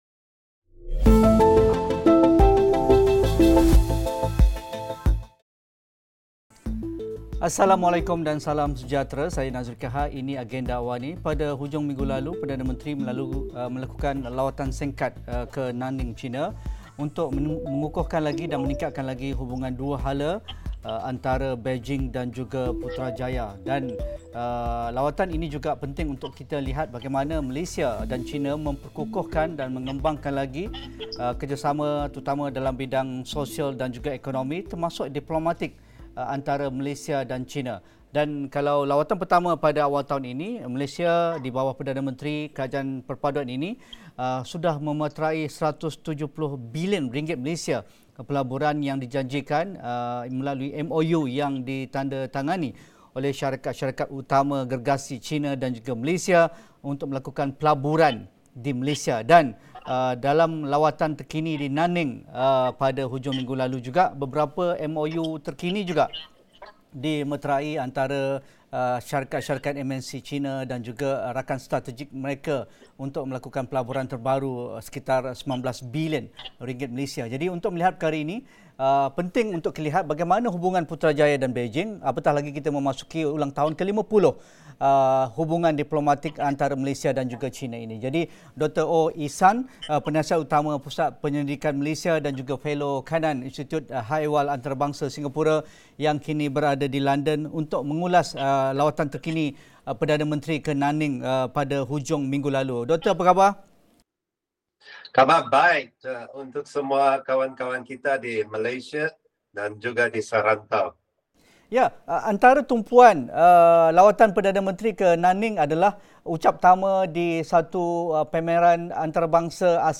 Sejauh mana impak lawatan Perdana Menteri, Datuk Seri Anwar Ibrahim ke China pada Ahad dapat mengukuhkan lagi hubungan dua hala dan mempertingkat perdagangan dan pelaburan antara Malaysia-China? Diskusi 8.30 malam